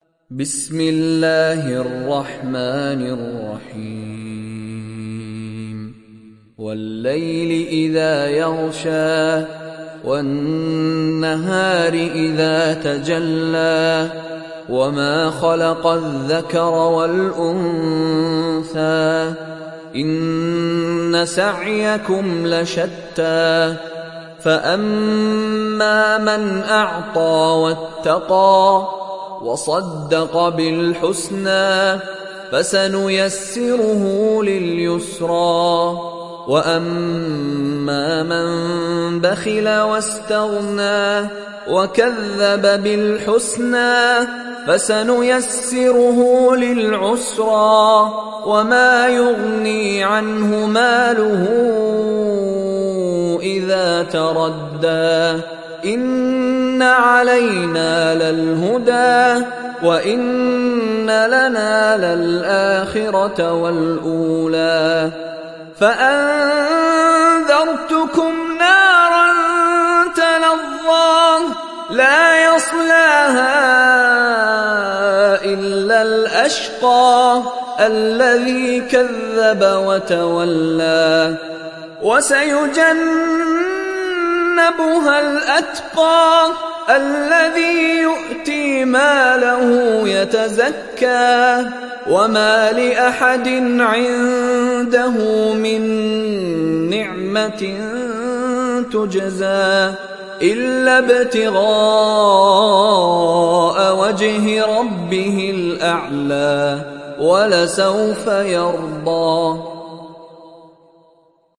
Surah Al Layl Download mp3 Mishary Rashid Alafasy Riwayat Hafs from Asim, Download Quran and listen mp3 full direct links